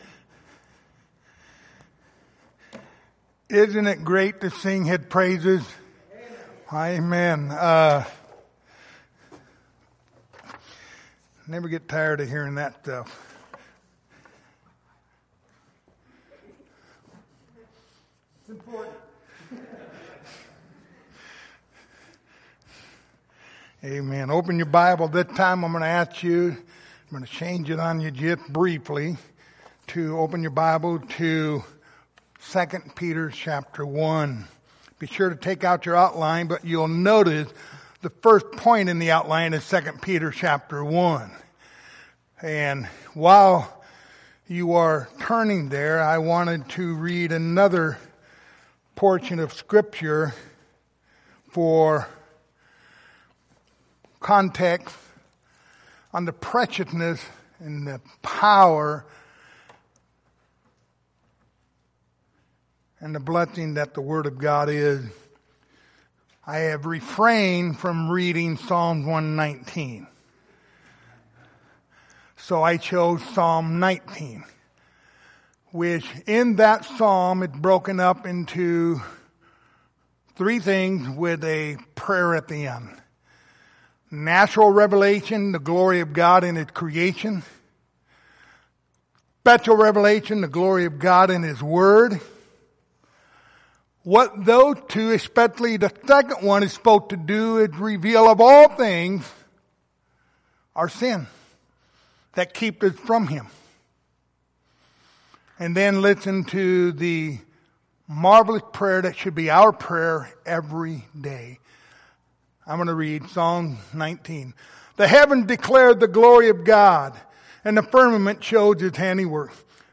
Pastoral Epistles Passage: 2 Timothy 3:16-17 Service Type: Sunday Morning Topics